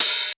CYM XCHEEZ01.wav